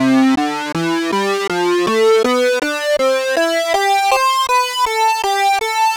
Track 16 - Synth 05.wav